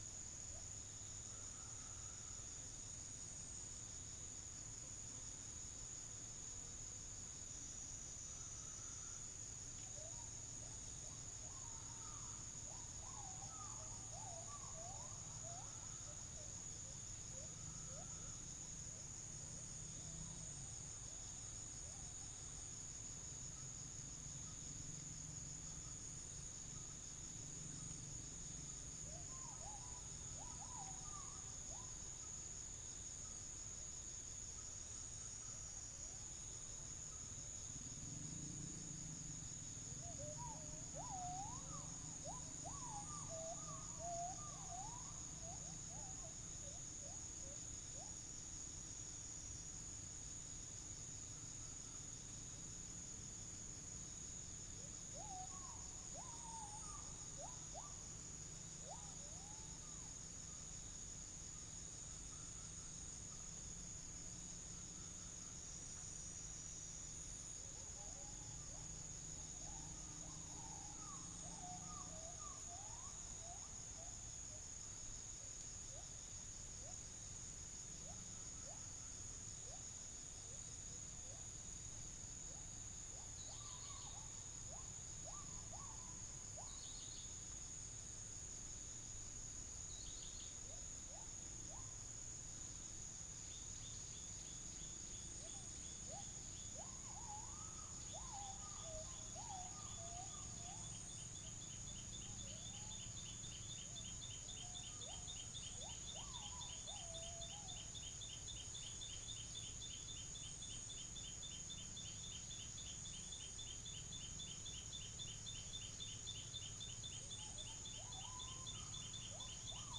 Geopelia striata
Trichixos pyrropygus
Psilopogon duvaucelii
Pelargopsis capensis
Orthotomus ruficeps
Orthotomus sericeus